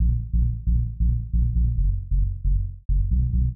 KIN Sub Riff Bb-Gb-B.wav